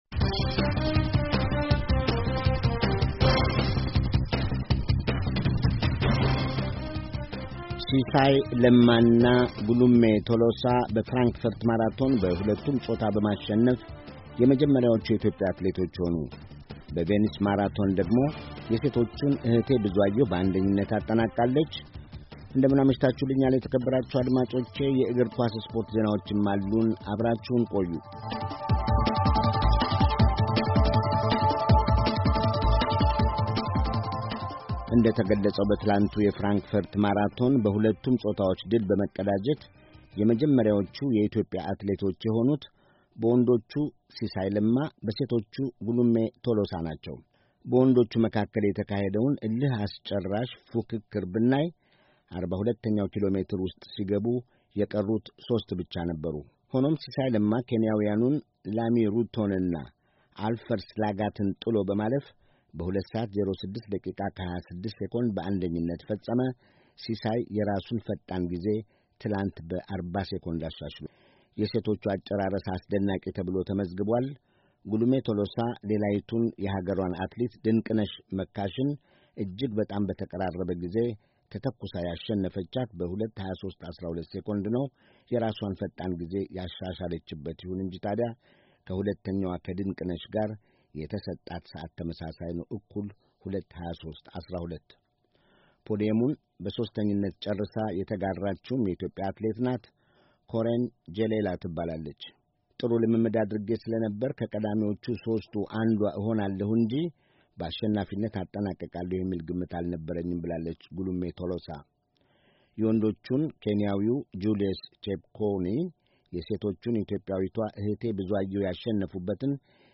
ዘገባ